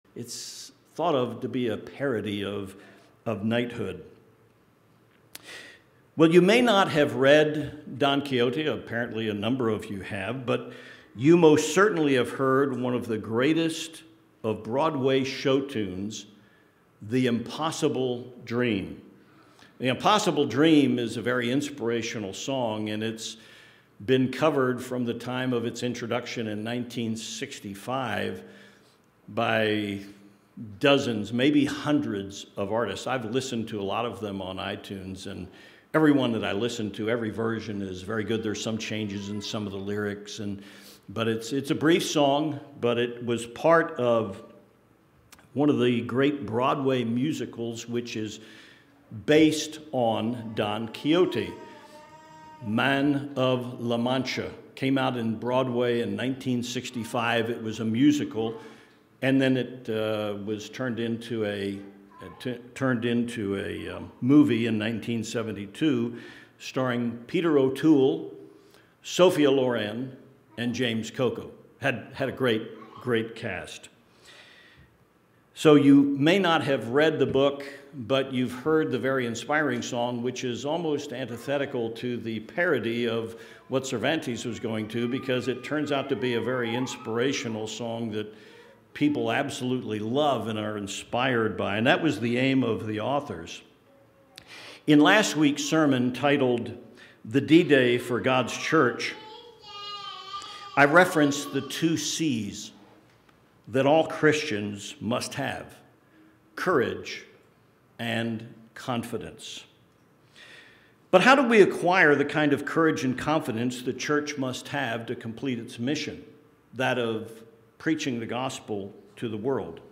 This is the second sermon in a series on developing courage and confidence as a Christian, qualities we will all need to complete our mission of preaching the Gospel.